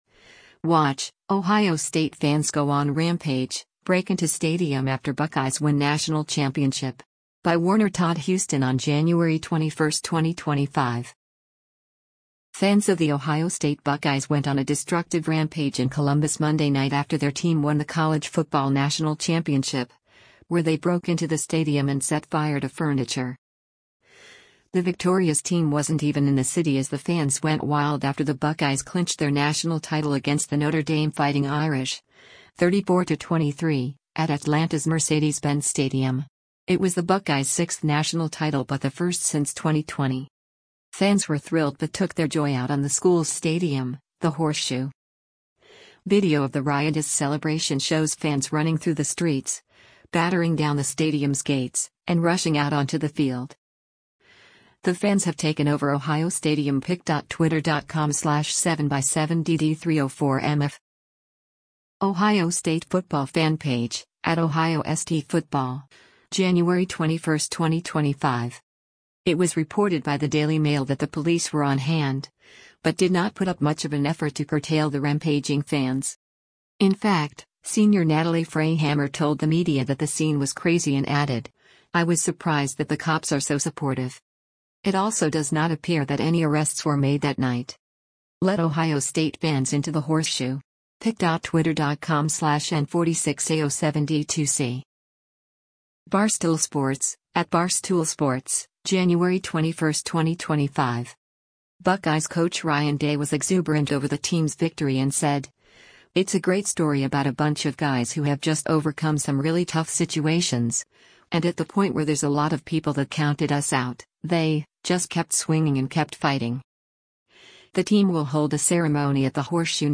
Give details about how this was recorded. Video of the riotous celebration shows fans running through the streets, battering down the stadium’s gates, and rushing out onto the field.